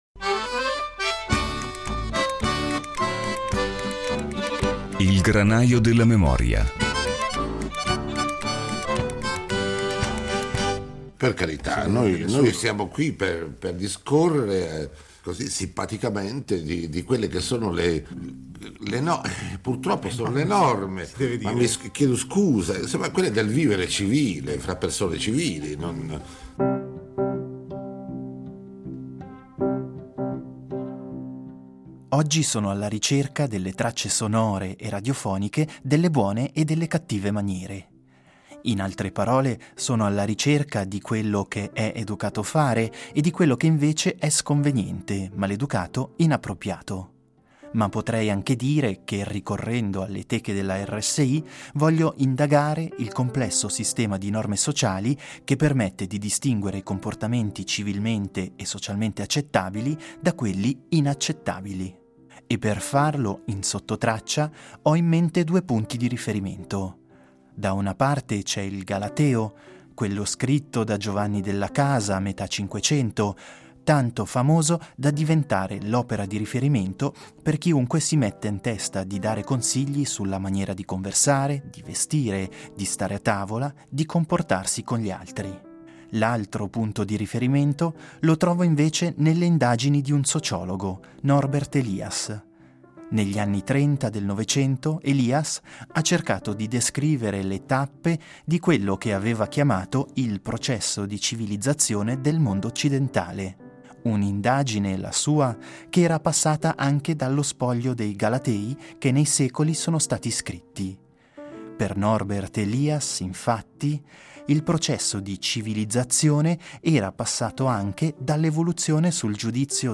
Sono queste le coppie di opposti attorno alle quali oscilleranno i frammenti sonori ritrovati tra le teche della RSI. Sullo sfondo il sistema di norme sociali, variabili nel tempo, che tentano più o meno formalmente di distinguere i comportamenti civilmente e socialmente accettabili da quelli inaccettabili.